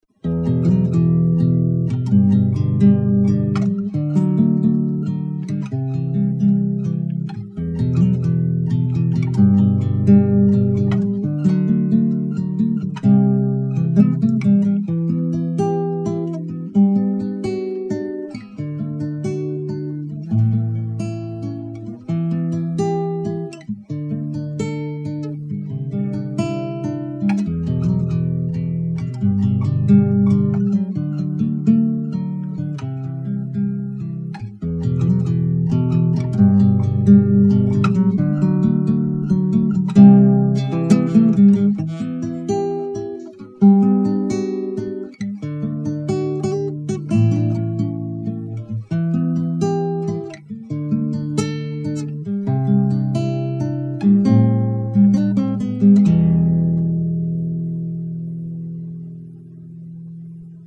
ми-минор